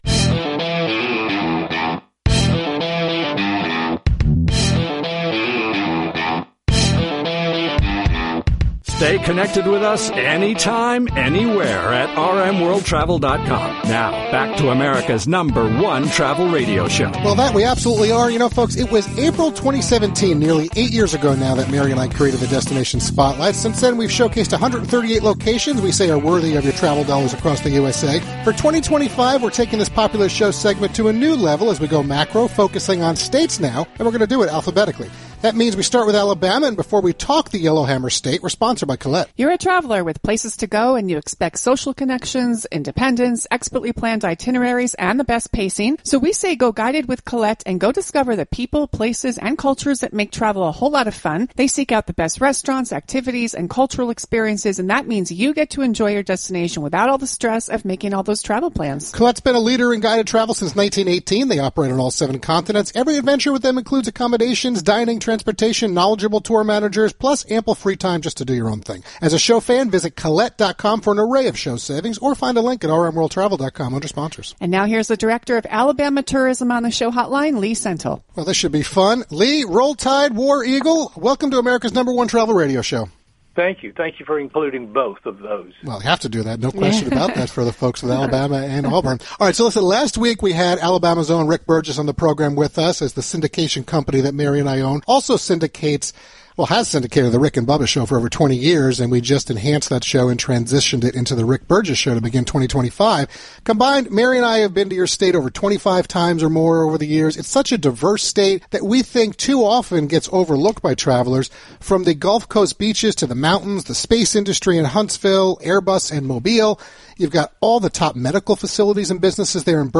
Lee Sentell, Director of Tourism
Lee Sentell is Alabama’s Director of Tourism and he and his team are responsible for all things travel/tourism offerings in the State — and he joined us to share an overview while extending the welcome mat.